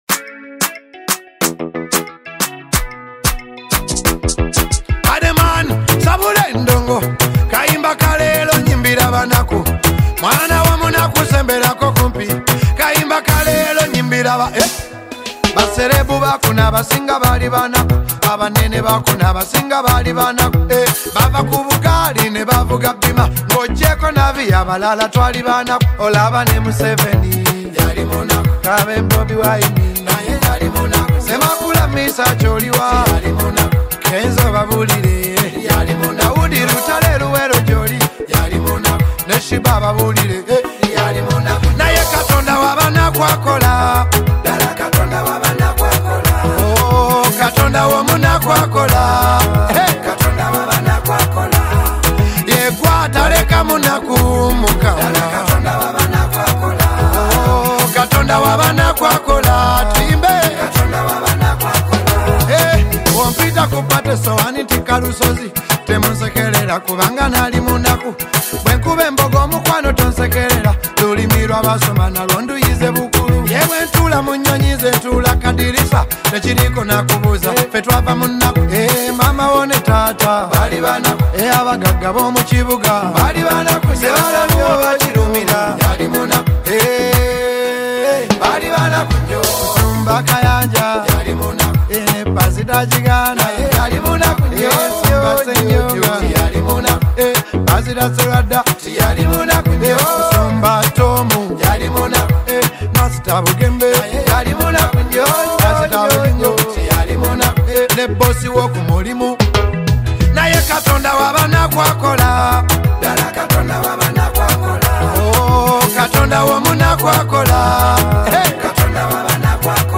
Genre: Gospel Music